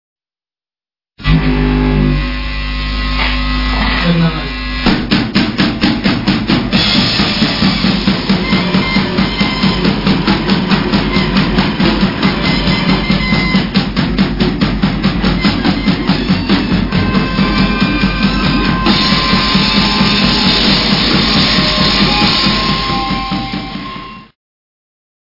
1980年京都のスタジオで録音された未発表テイクも収録